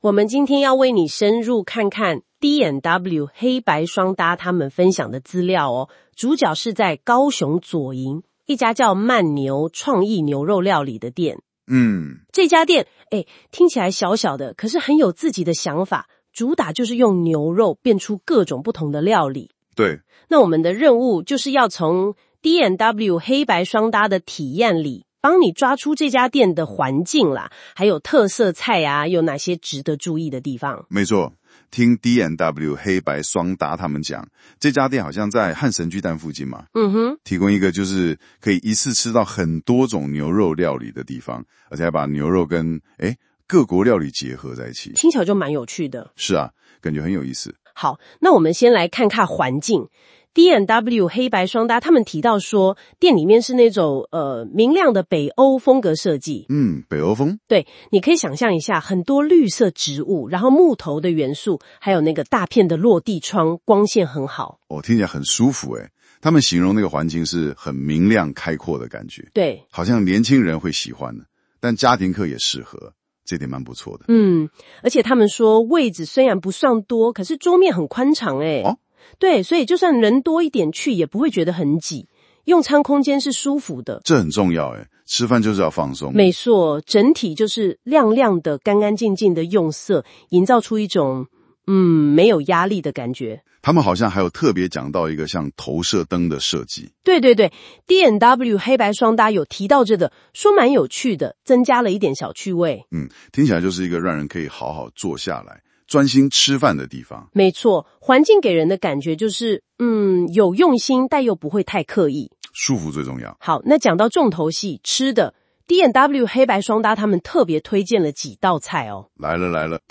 新功能!現在用【說】的方式介紹哦!
我們請兩位主持人專業講解，深度介紹D&W黑白雙搭推薦內容